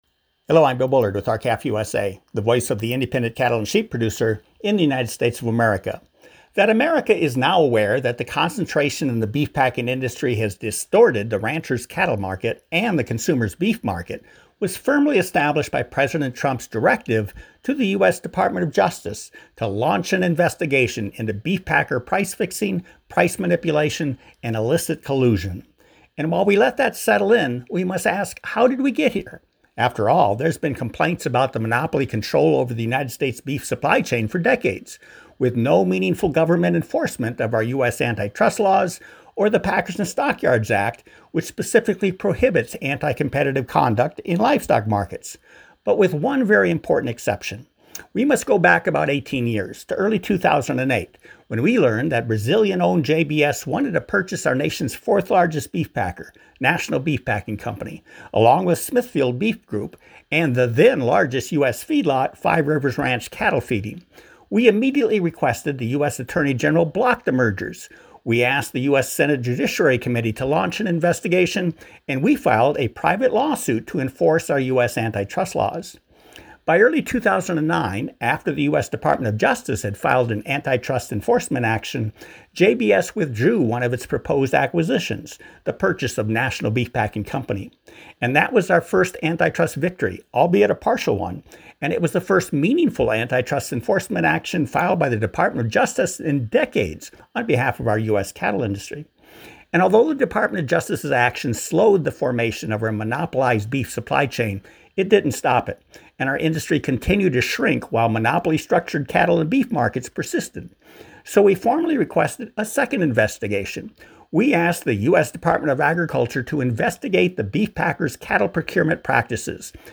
Weekly Address: A Legacy of Antitrust Enforcement